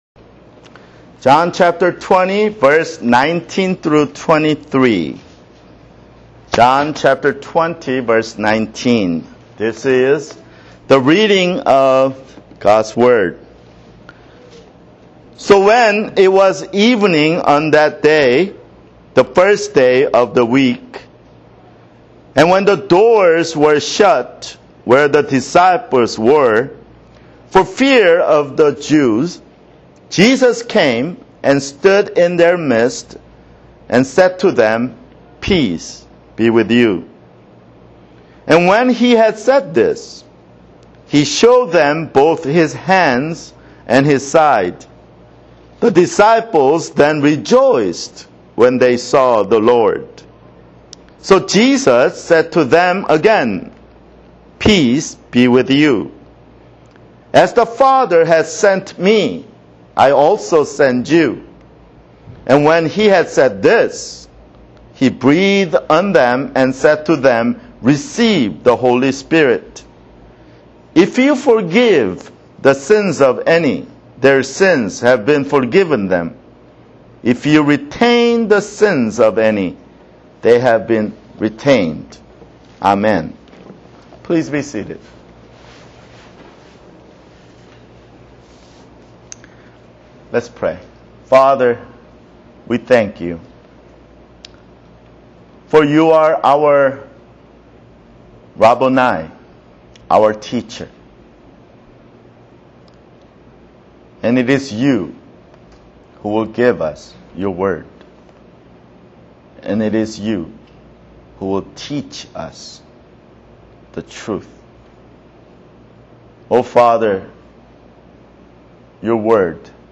[Sermon] John 20:19-23 (3)